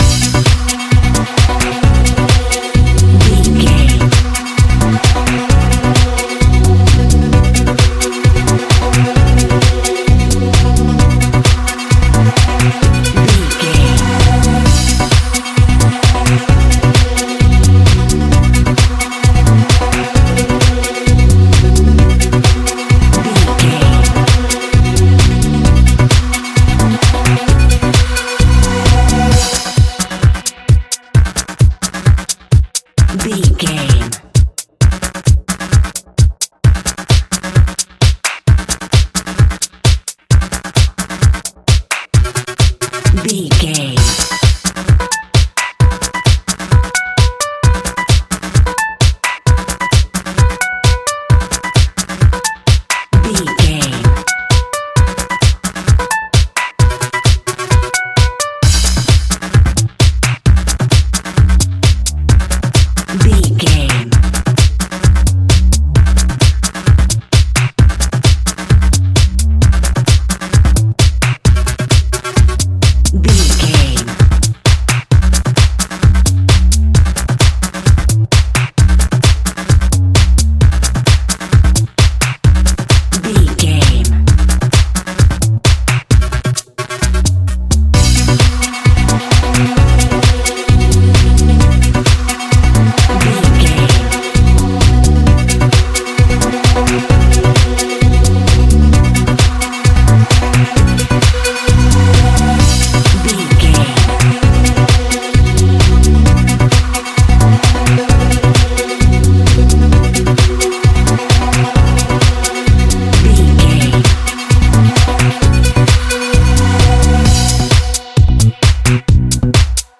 Aeolian/Minor
groovy
smooth
hypnotic
drum machine
synthesiser
disco
electro house
funky house